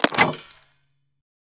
Mode Chain Break Sound Effect
mode-chain-break.mp3